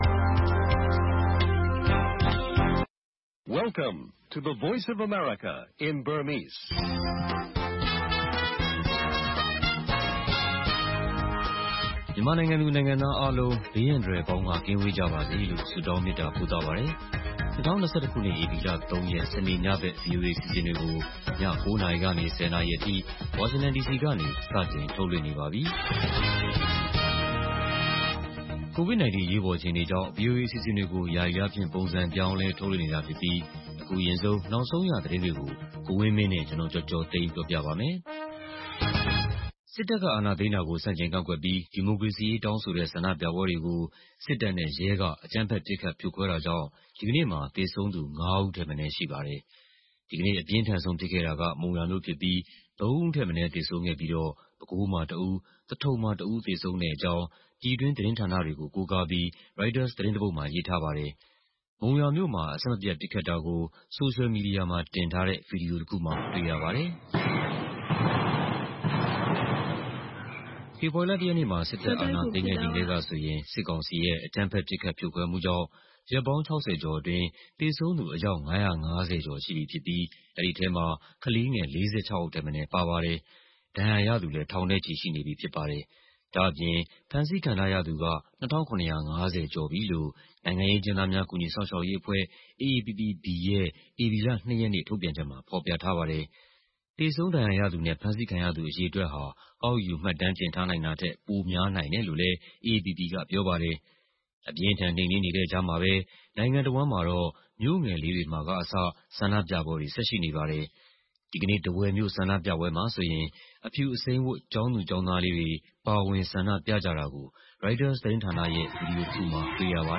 ဆန္ဒပြပွဲနဲ့ အကြမ်းဖက်ဖြိုခွဲမှု သတင်းတွေနဲ့အတူ လူ့အခွင့်အရေးဆိုင်ရာ ဒို့အသံအစီအစဉ်၊ ၂၀၀၈ မြန်မာ့ မယ်စကြာဝဠာနဲ့ မေးမြန်းခန်းတို့အပါအဝင် ည ၉း၀၀-၁၀း၀၀ နာရီ ရေဒီယိုအစီအစဉ်။